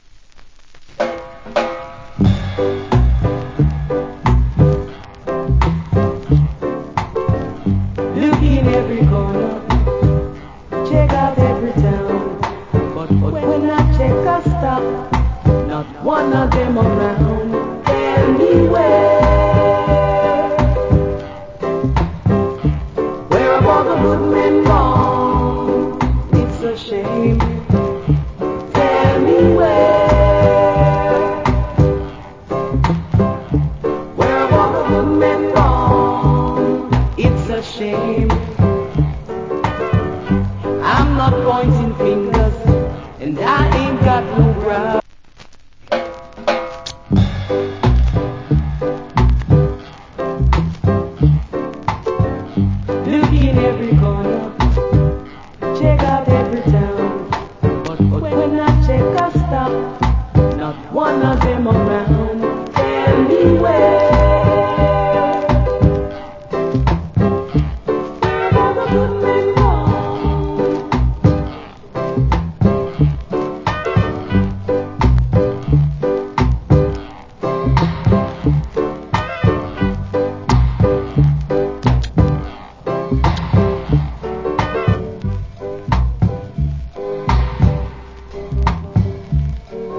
Nice Female Reggae Vocal.